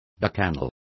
Complete with pronunciation of the translation of bacchanals.